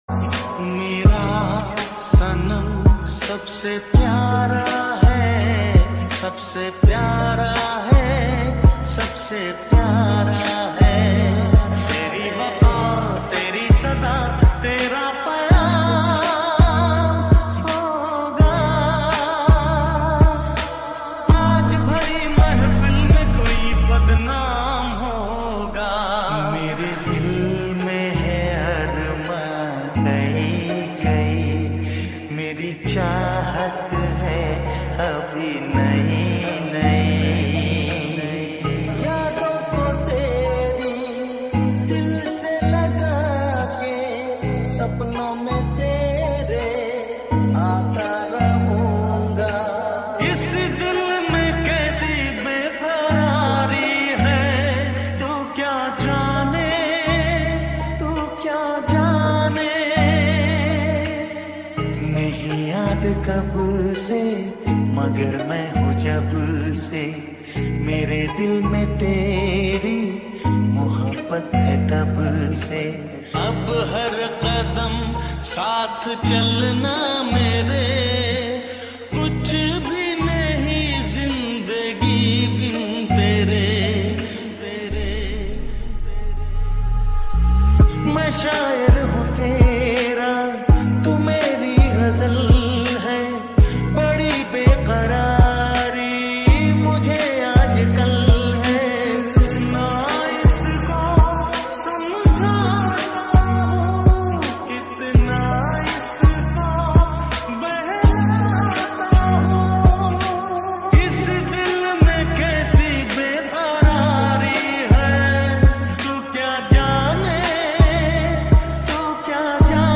Mashup